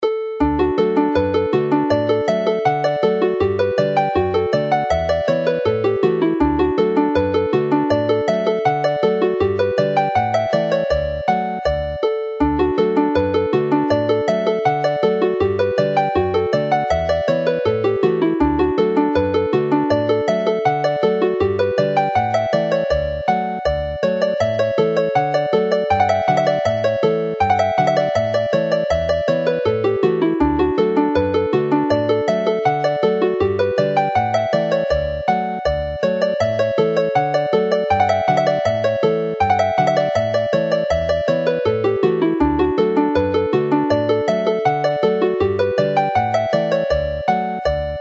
generally heard in sessions played as a reel